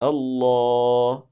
d ـــ Försvagar den när man stannar, som i: